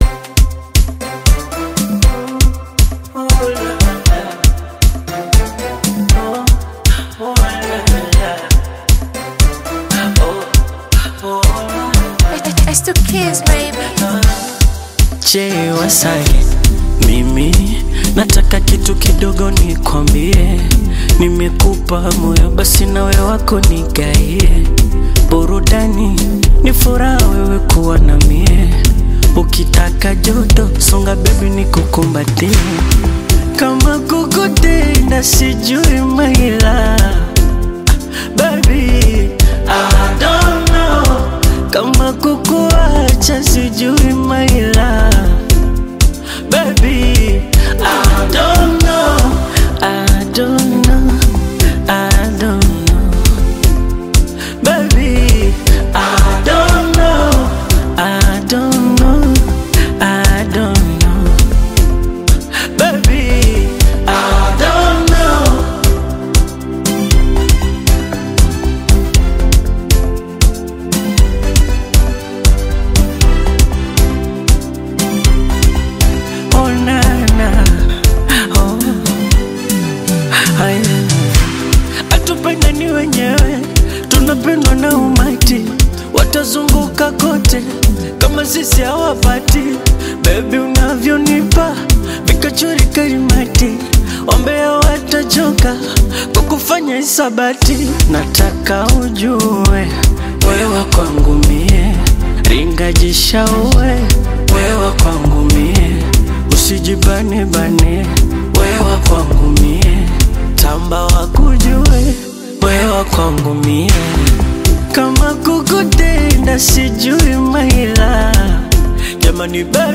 emotive Bongo Flava single
Genre: Bongo Flava